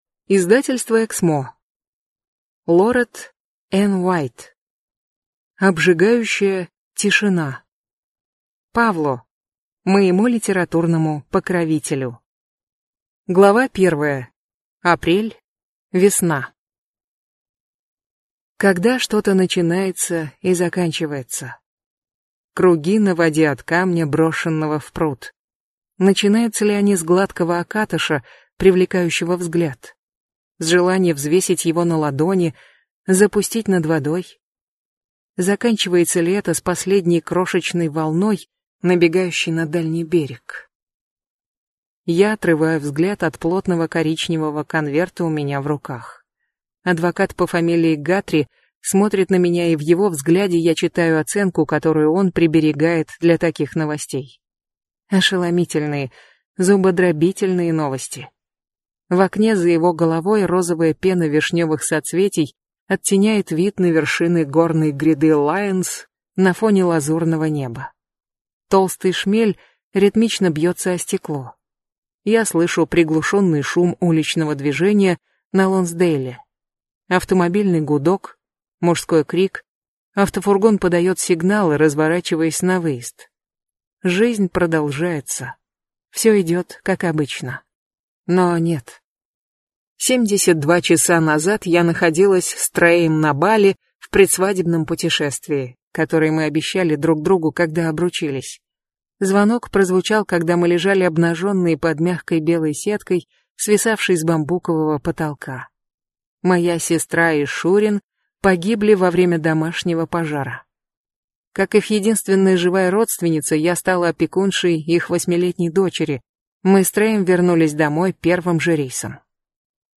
Аудиокнига Обжигающая тишина